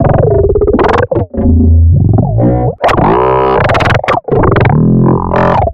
Звуки глитч-эффектов
Звук искажения кадра